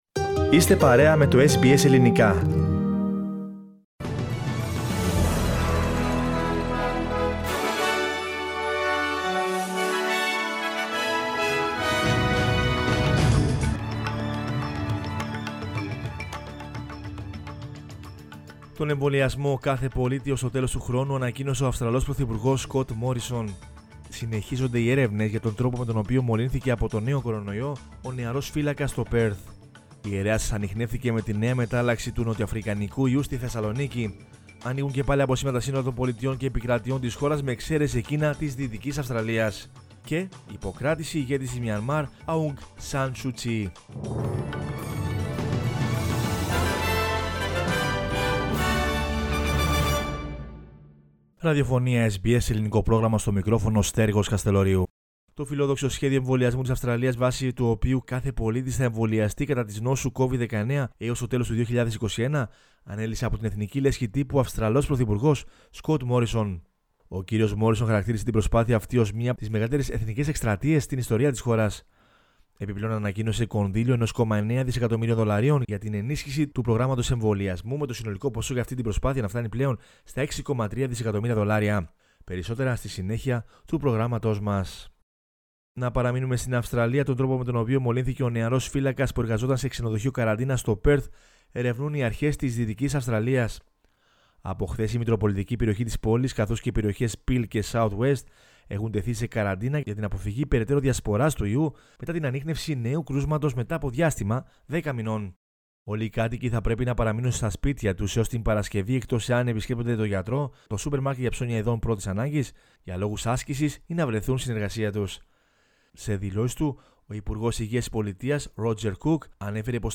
News in Greek from Australia, Greece, Cyprus and the world is the news bulletin of Monday 1 February 2021.